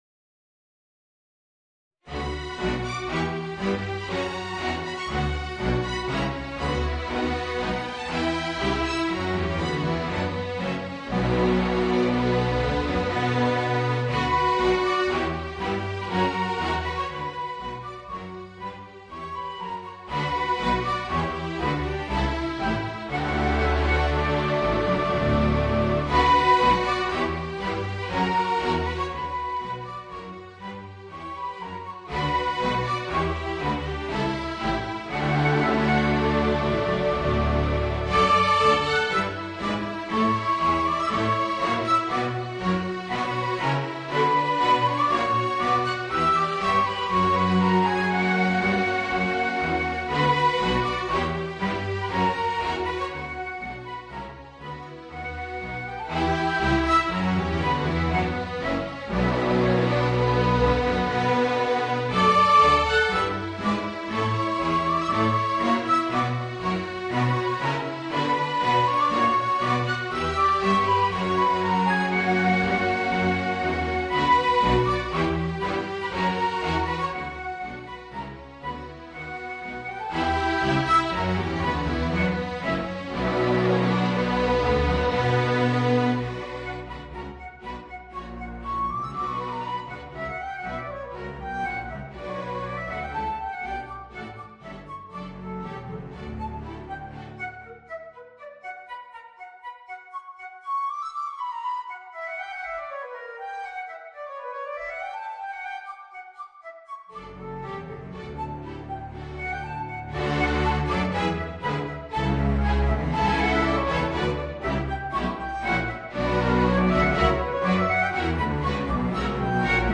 Voicing: Trombone and String Orchestra